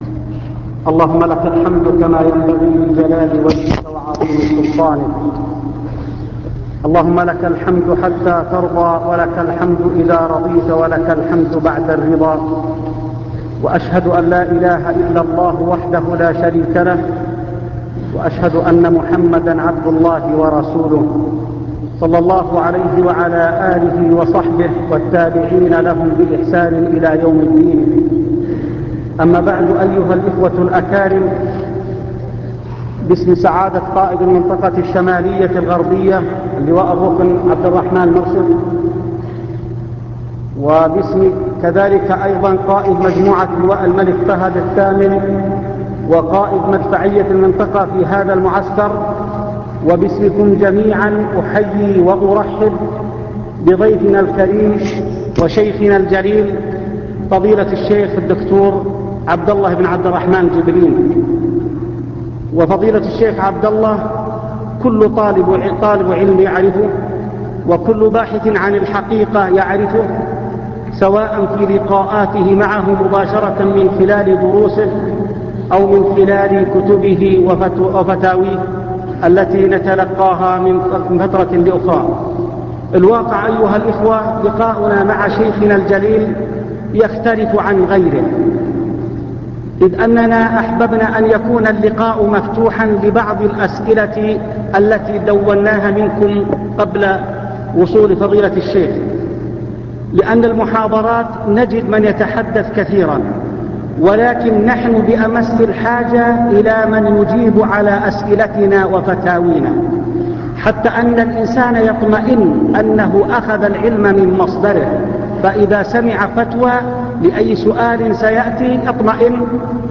المكتبة الصوتية  تسجيلات - لقاءات  كلمة في القوات المسلحة